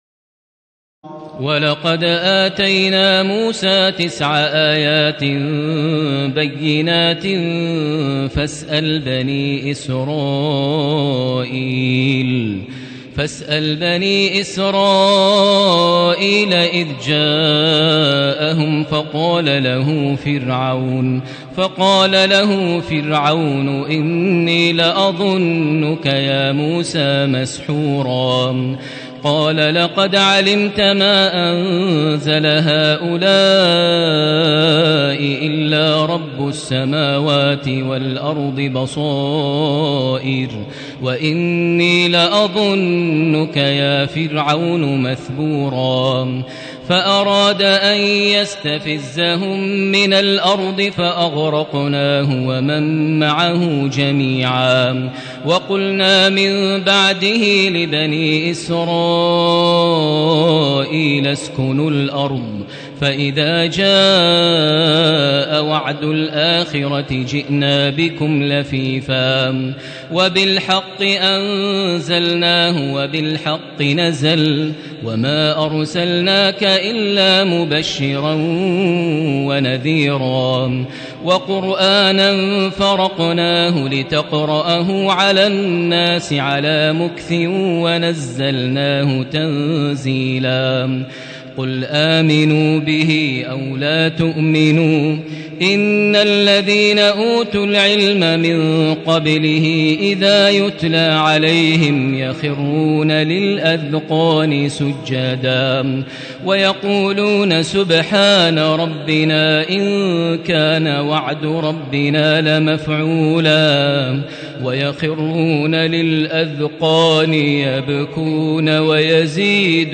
تراويح الليلة الرابعة عشر رمضان 1437هـ من سورتي الإسراء (101-111) والكهف (1-82) Taraweeh 14 st night Ramadan 1437H from Surah Al-Israa and Al-Kahf > تراويح الحرم المكي عام 1437 🕋 > التراويح - تلاوات الحرمين